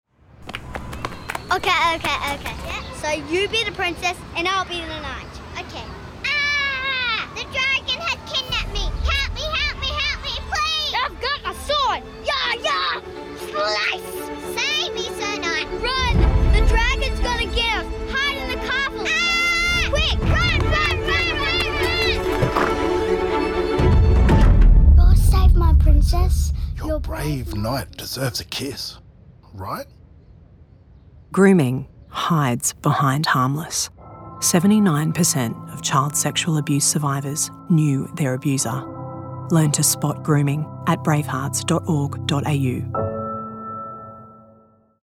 Radio
The voice talent heard in the three chilling radio spots came from Brisbane-based voiceover agency SnackBox.